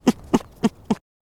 Low-amplitude calls
Grunt
Varecia_grunts1.ogg.mp3